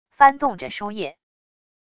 翻动着书页wav音频